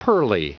Prononciation du mot pearly en anglais (fichier audio)
Prononciation du mot : pearly